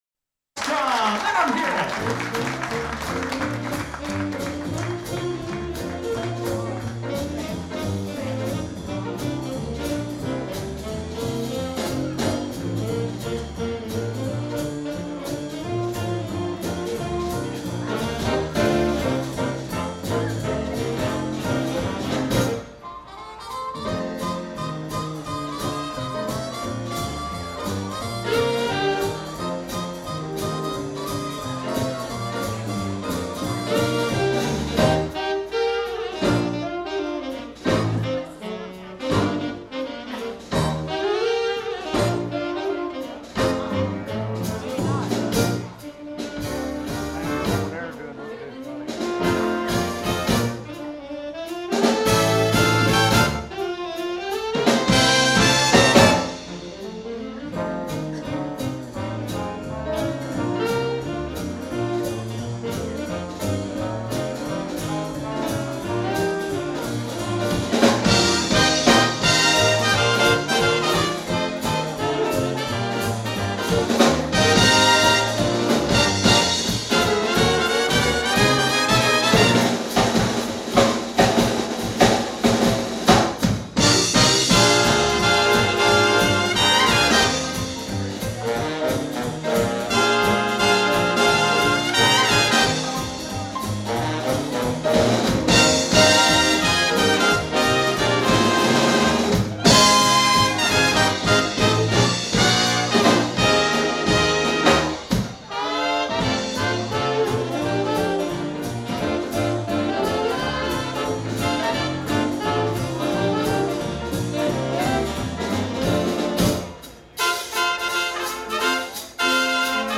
Swing
live recording